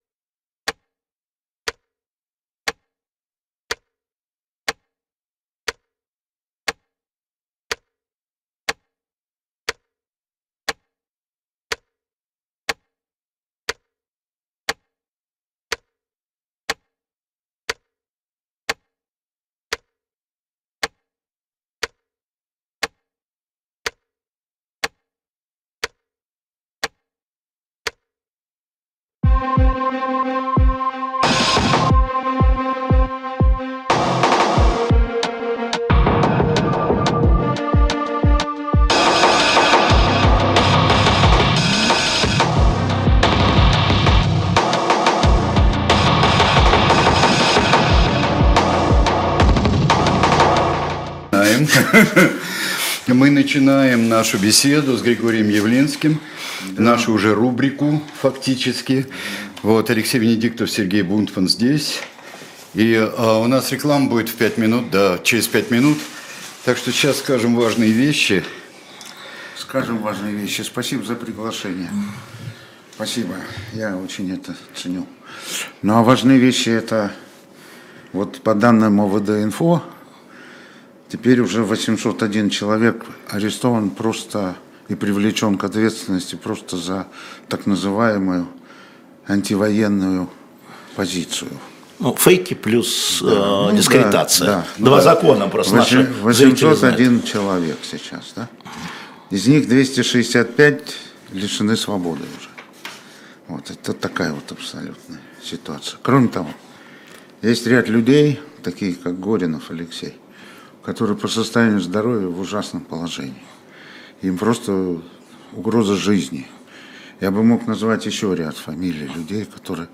Эфир ведут Алексей Венедиктов и Сергей Бунтман